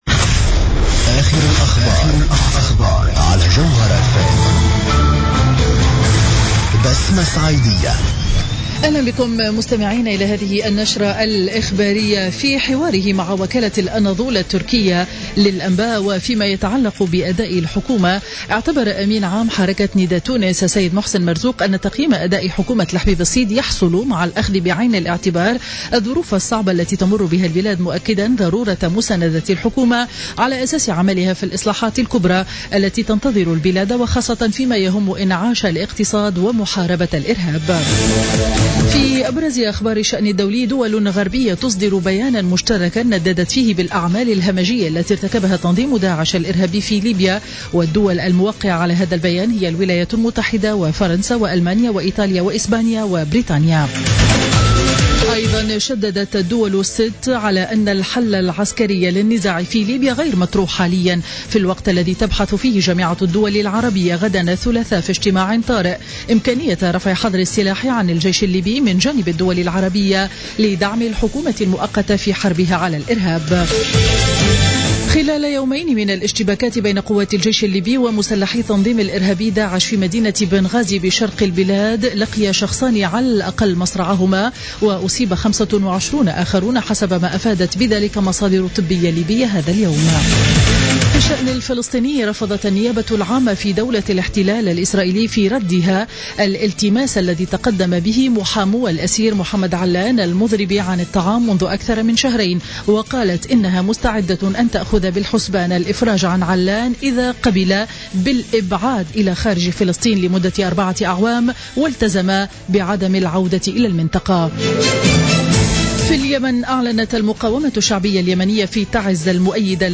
نشرة أخبار منتصف النهار ليوم الاثنين 17 أوت 2015